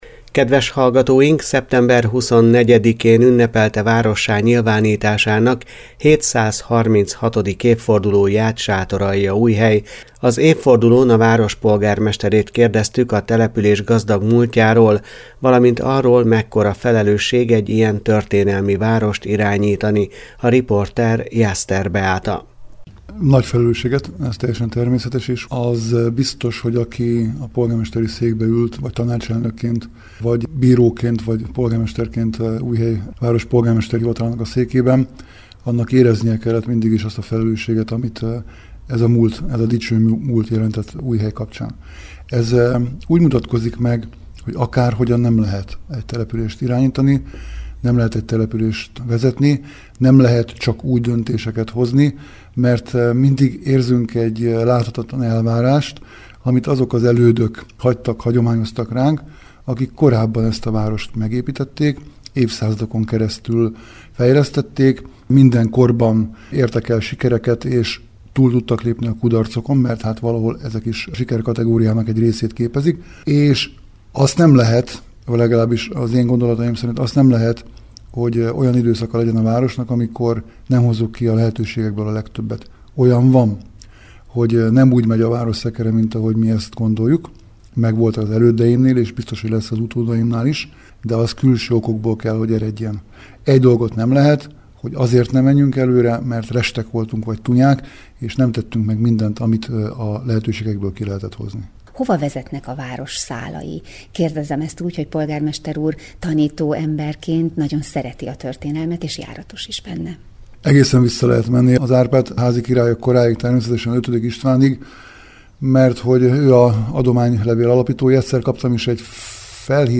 Szeptember 24-én ünnepelte várossá nyilvánításának 736. évfordulóját Sátoraljaújhely. Az évfordulón a város polgármesterét kérdeztük a település gazdag múltjáról, valamint arról, mekkora felelősség egy ilyen történelmi várost irányítani.